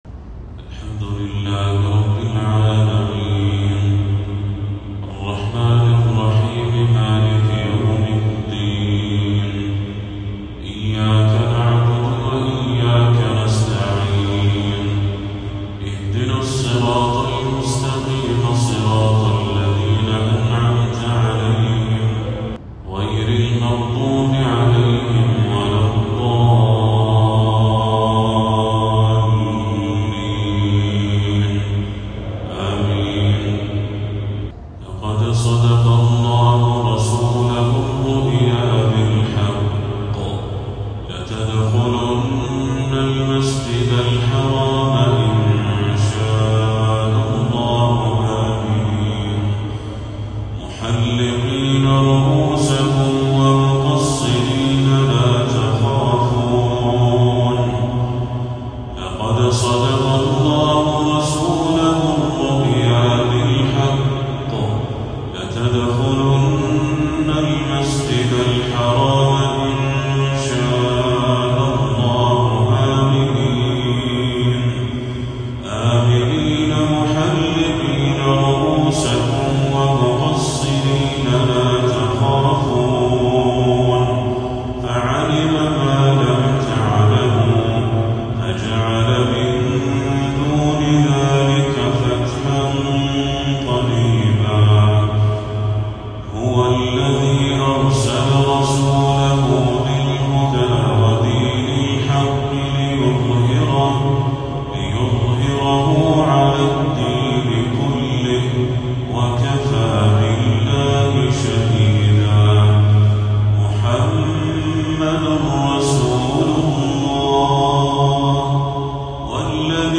تلاوة رائعة لخواتيم سورتي الفتح و ق
عشاء 5 ربيع الأول 1446هـ